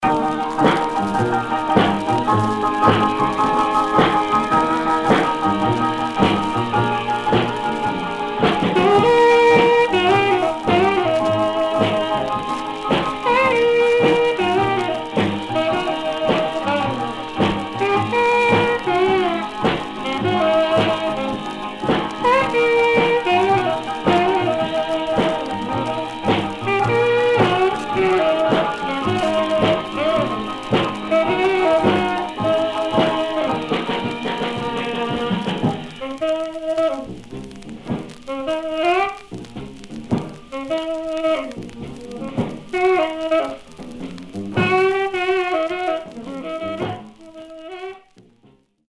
Stereo/mono Mono
R&B Instrumental